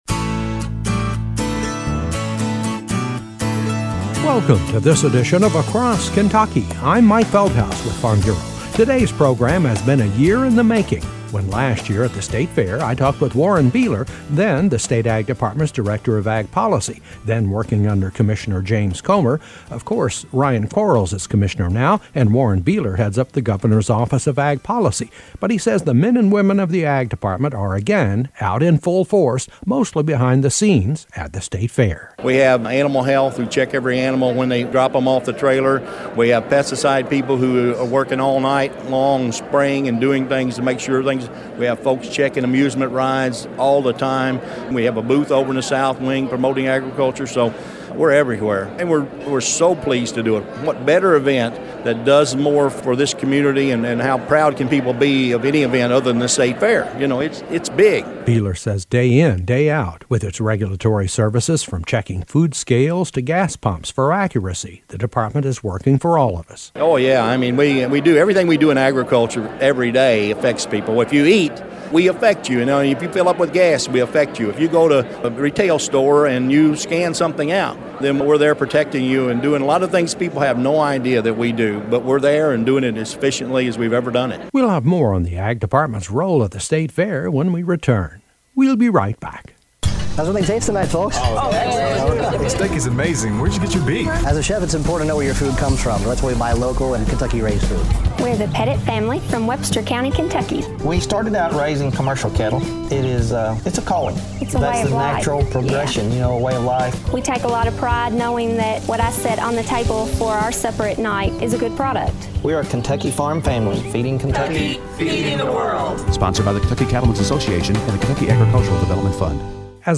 A feature report on the work behind-the-scenes by the men and women of the Ky Dept of Ag to help put on the Ky State Fair. Warren Beeler, former ag department superintendent of the state fair country ham competition, now Executive Director of the Governor’s Office of Ag Policy is featured.